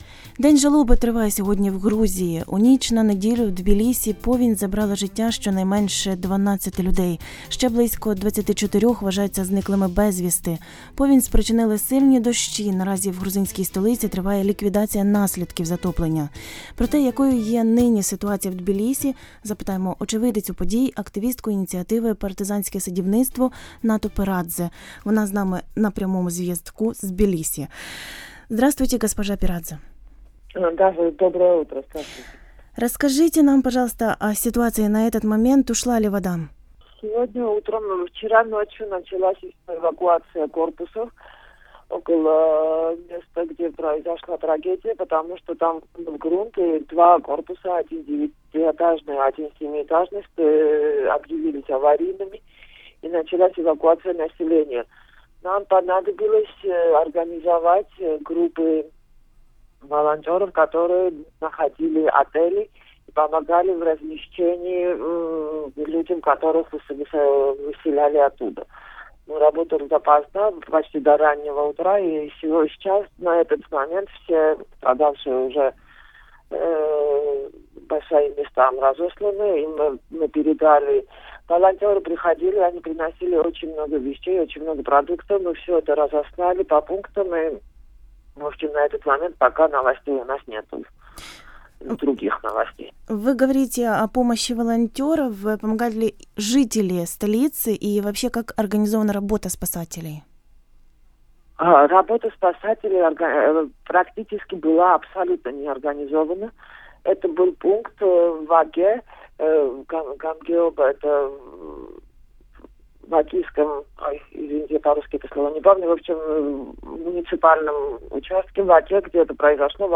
Повінь у Тбілісі відбулася через засмічення річки – очевидиця